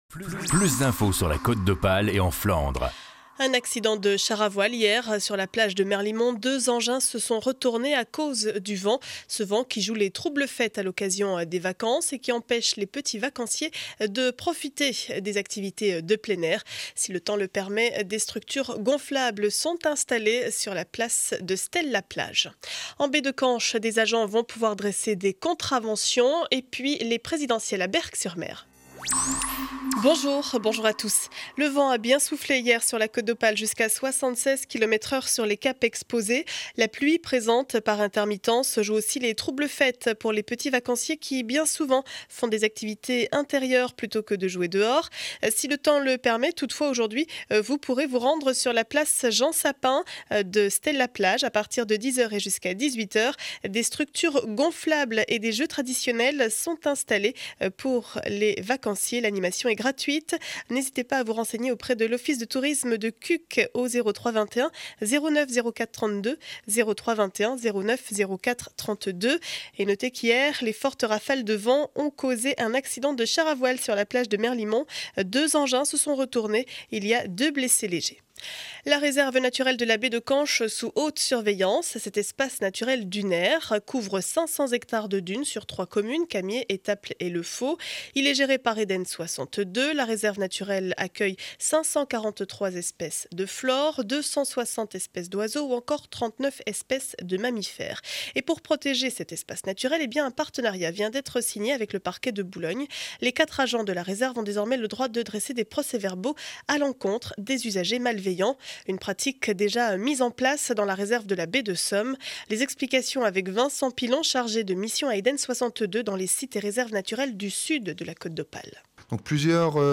Journal du jeudi 26 avril 2012 7 heures 30, édition du Montreuillois.